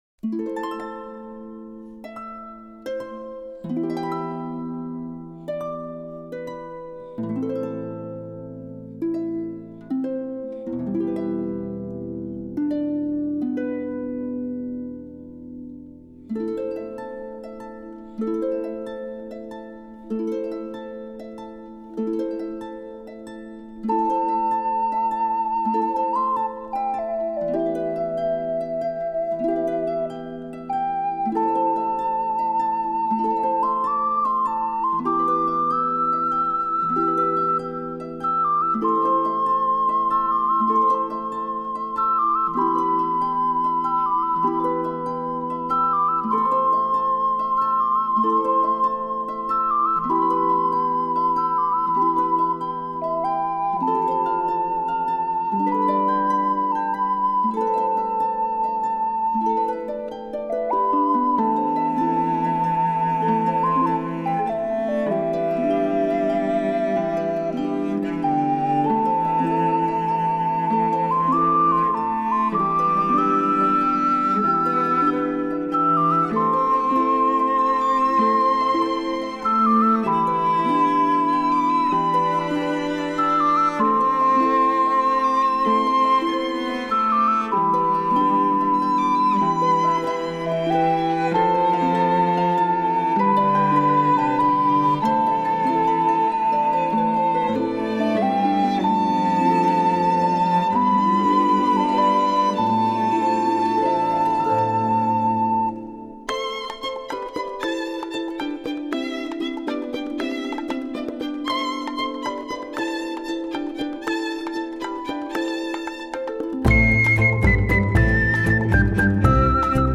Genre: Newage.